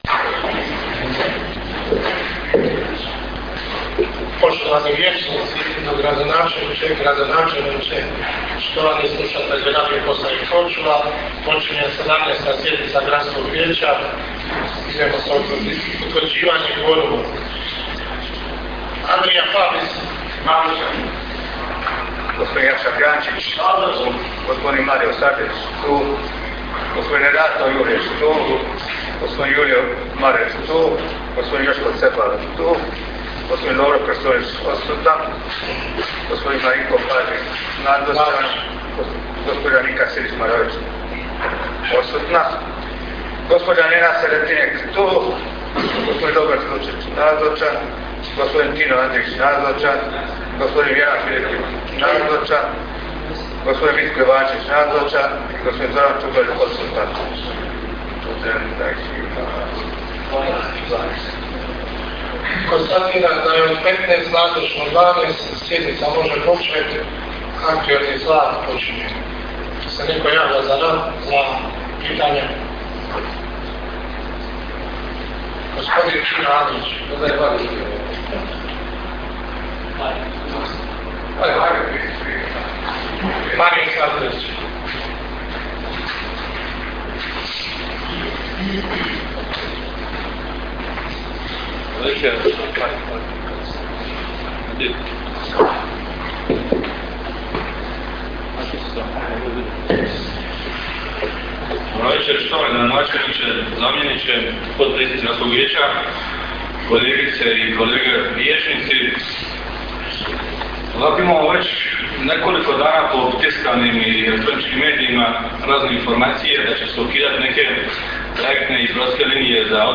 Sjednica je održana 3. prosinca (ponedjeljak) 2012. godine , s početkom u 18.35 sati, u Gradskoj vijećnici u Korčuli. Sjednici je predsjedao Marinko Pažin – potpredsjednik Vijeća.
Sjednica se prenosila putem Radio Korčule.